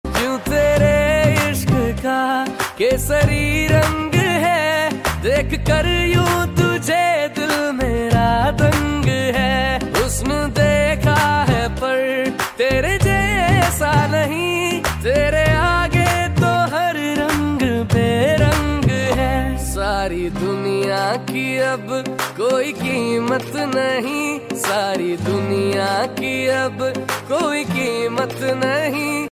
Hindi Songs
• Simple and Lofi sound
• Crisp and clear sound